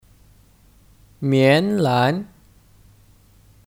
棉兰 (Mián lán 棉兰)